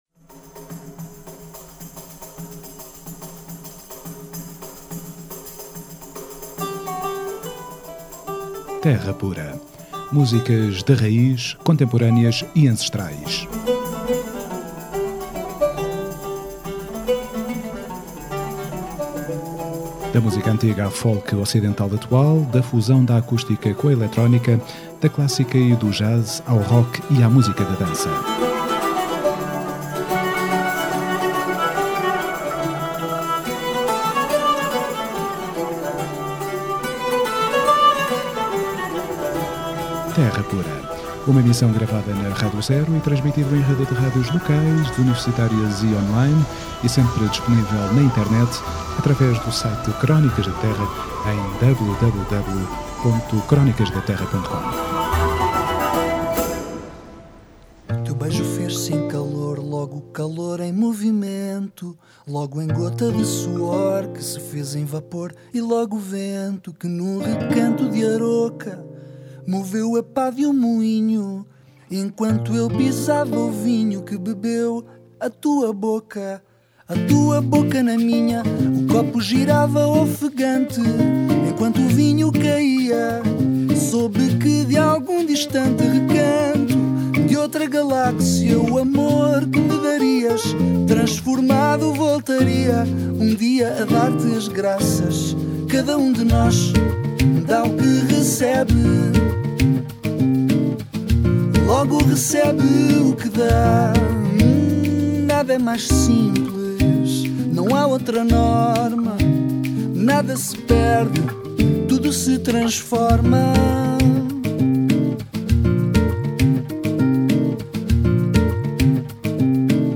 Terra Pura 23OUT13: Entrevista